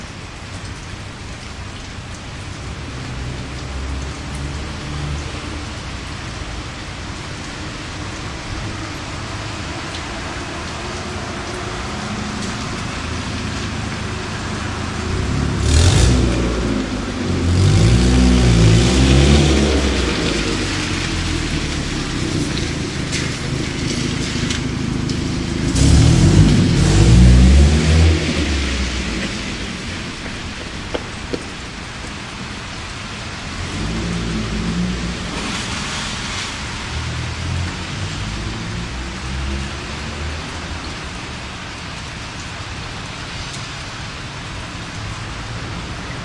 汽车换成驱动自动变速器
描述：汽车转入驱动器自动变速器
声道立体声